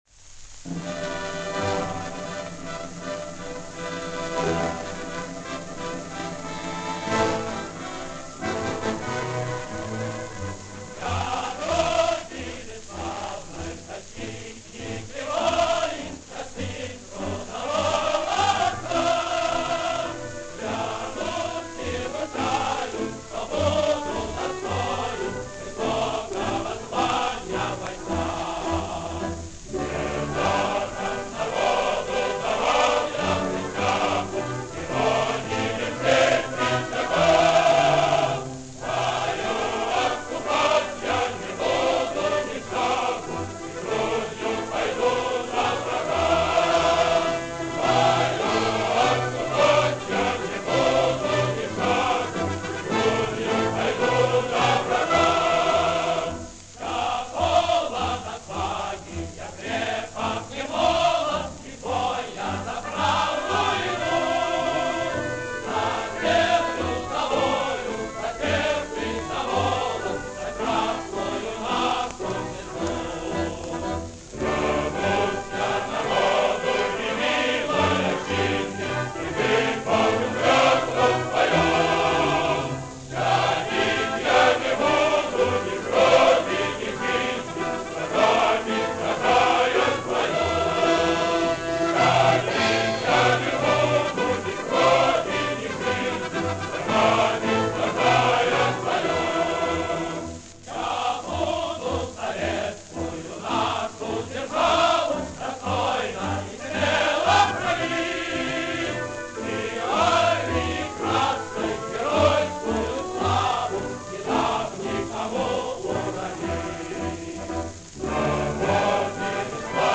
Еще одна очень редкая довоенная песня.